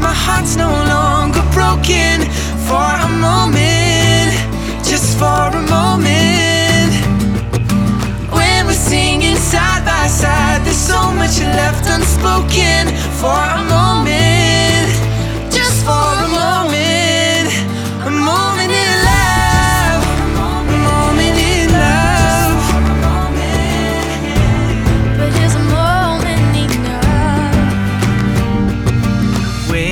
Genre: Soundtrack